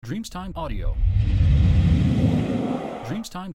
Das langsame Drehen Whoosh Effekt